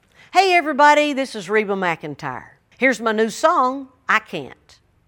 LINER Reba McEntire (I Can't) 2